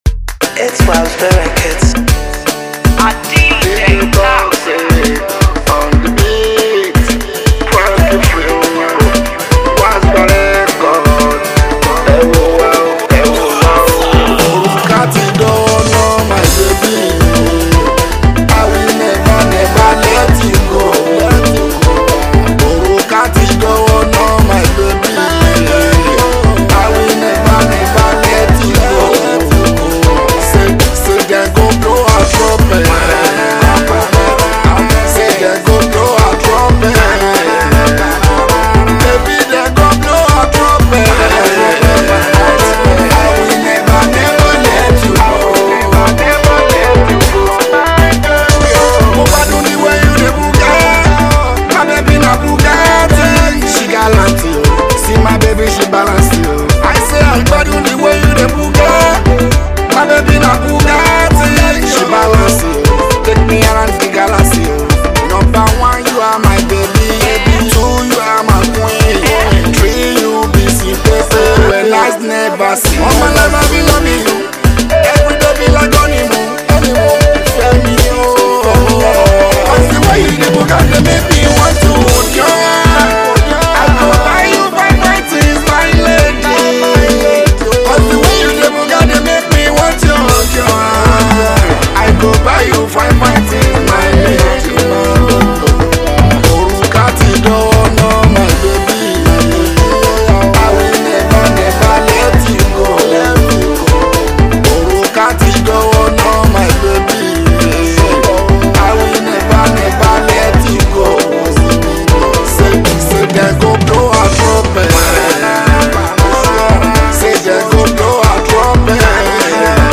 love song